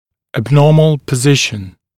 [æb’nɔːml pə’zɪʃn][эб’но:мл пэ’зишн]аномальное положение